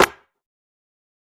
TC3Snare21.wav